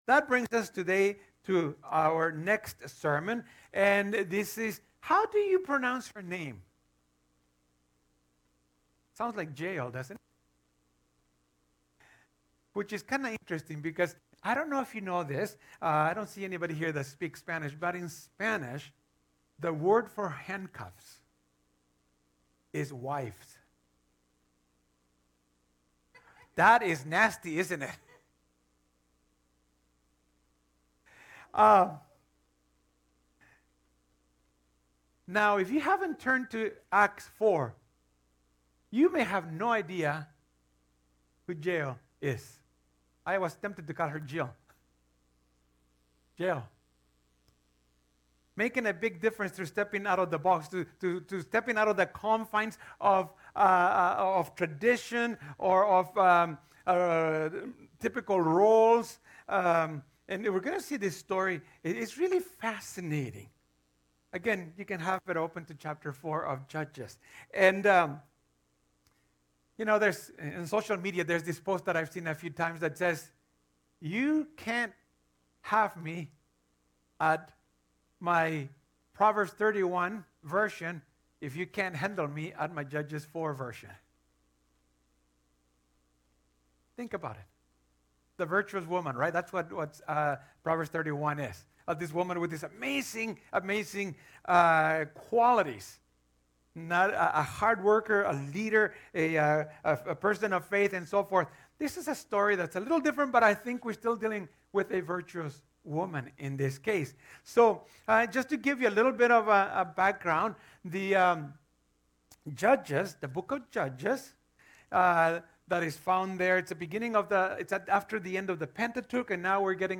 Sermons | Devon Community Church
The sermon concludes by offering three practical suggestions so we can make a big difference through stepping out of our comfort zones.